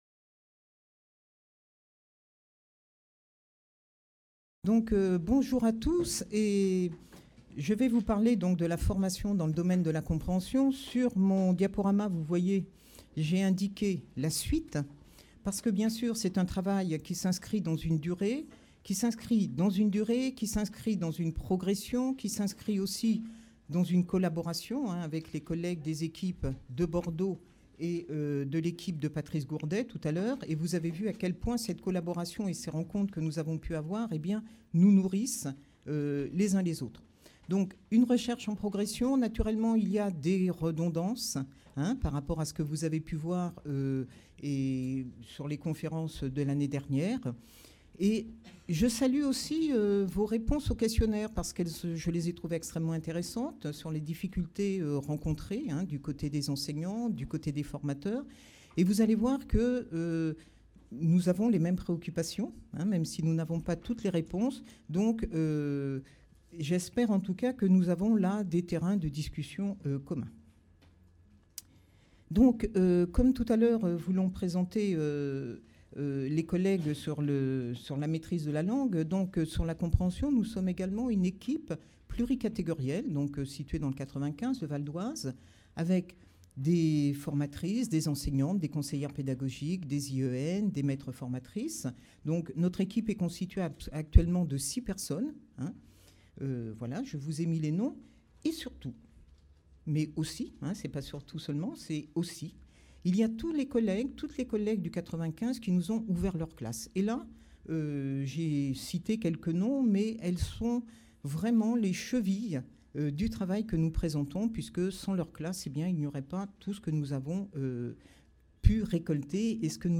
Cette intervention, à l'Ifé en octobre 2018, s’inscrit dans la continuité de la formation de novembre 2017 dont l'objet était de présenter les résultats de la recherche Lire-Ecrire (ici).